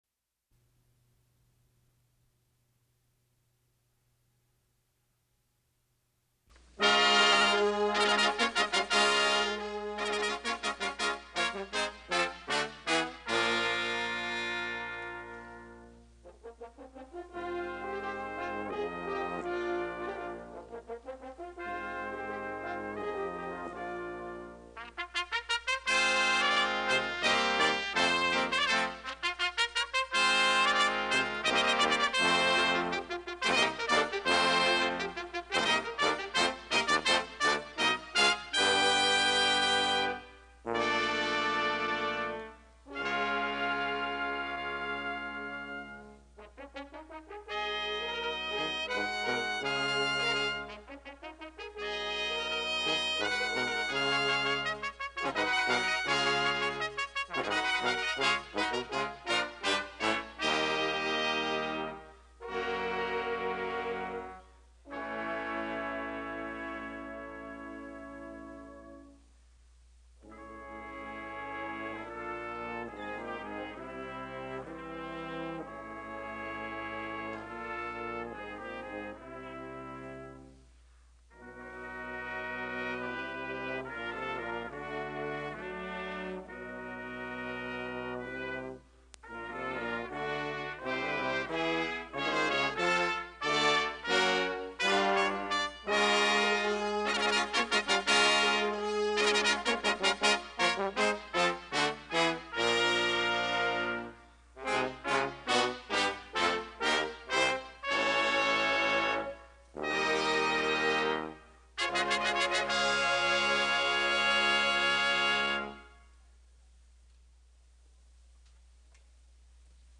Inauguration of Cecil Mackey Back Subjects: Presidents, Events Description: Inauguration of Cecil Mackey in 1979.
Original Format: Open reel audio tape